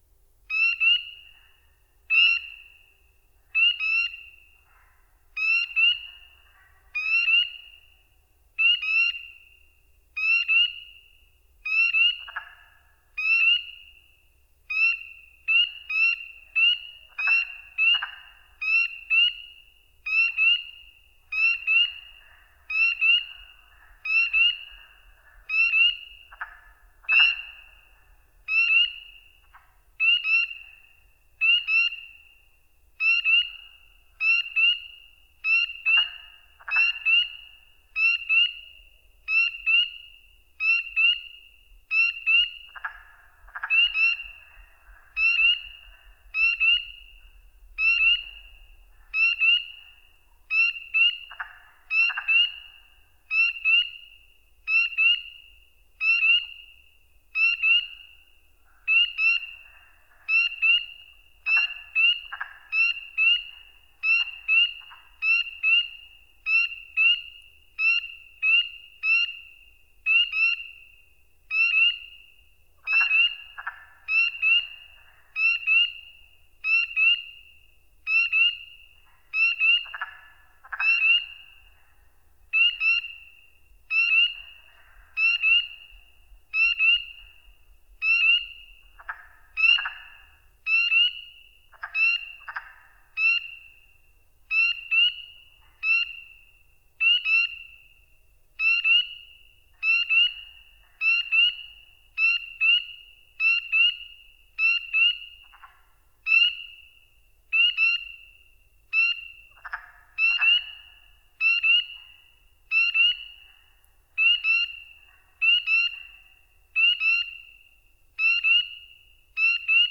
animal
Wetland Spring Peepers Sparse Others